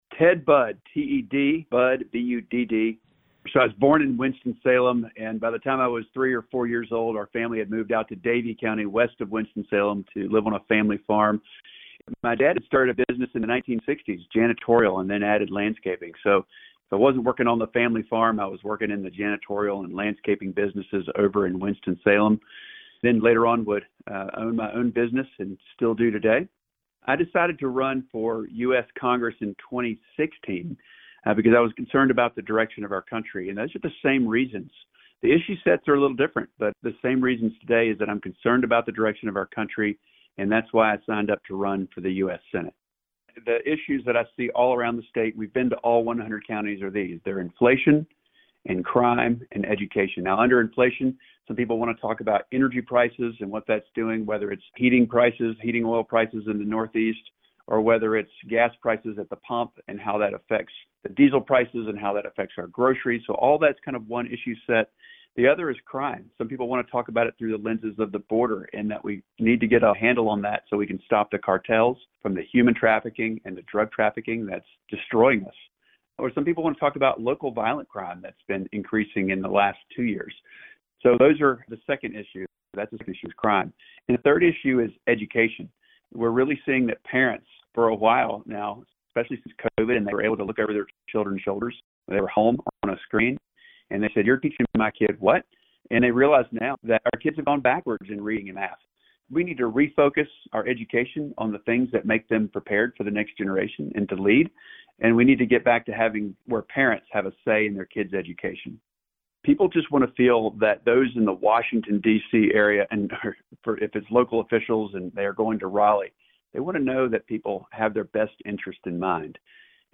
Each candidate answered the same set of questions regarding their decision to run for elected office, their backgrounds and what they wish for residents to think of when voting this fall.
Both Beasley and Budd spoke to 97.9 The Hill and Chapelboro to introduce themselves, and their candidacy.